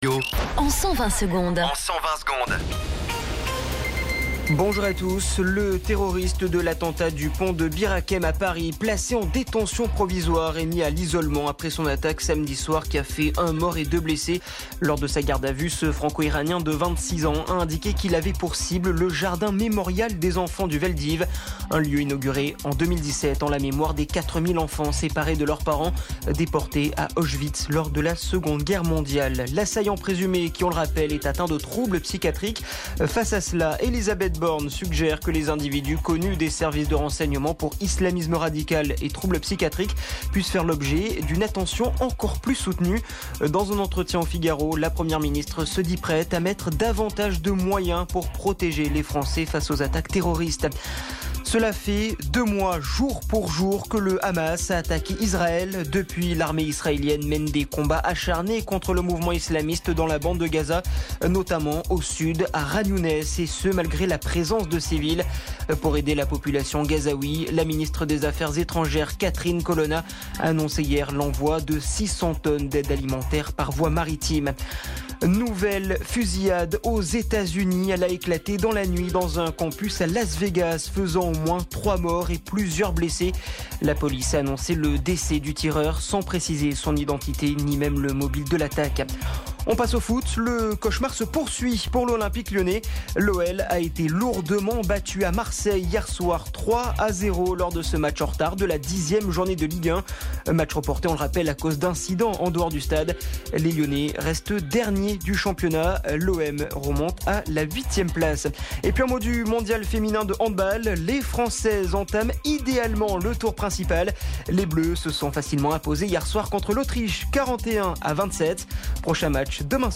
Flash Info National